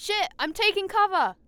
Voice Lines / Combat Dialogue